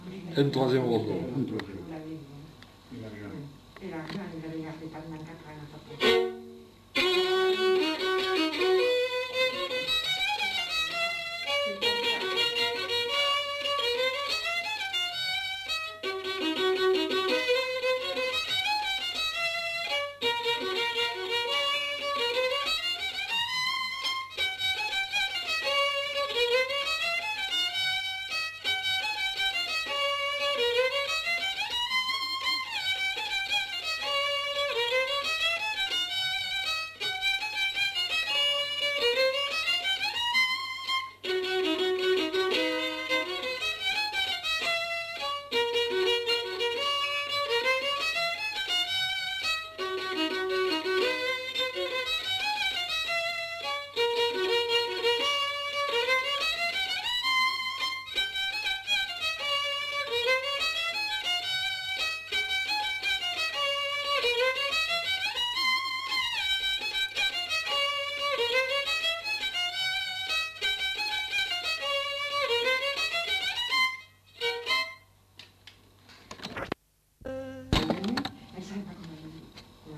Rondeau